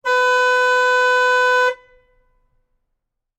Звуки гольфа
Дольше гудок